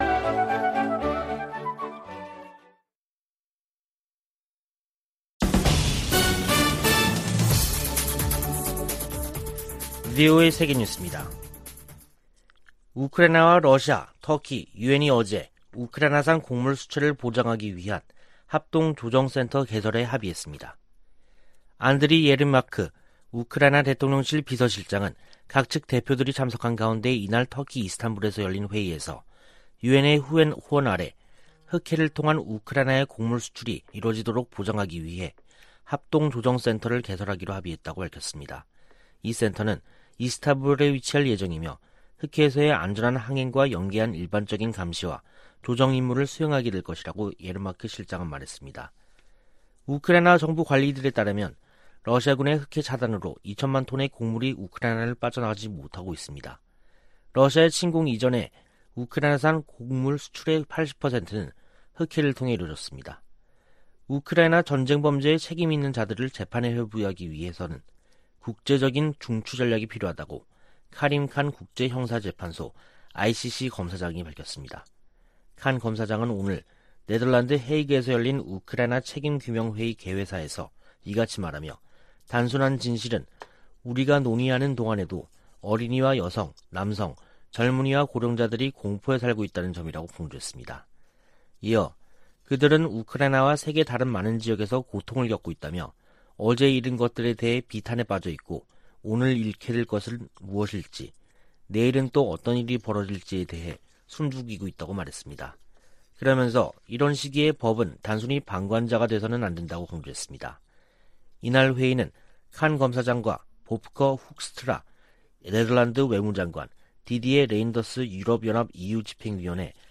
VOA 한국어 간판 뉴스 프로그램 '뉴스 투데이', 2022년 7월 14일 2부 방송입니다. 미국과 한국 공군이 처음으로 F-35A 스텔스 전투기가 참여하는 연합비행훈련을 실시했습니다.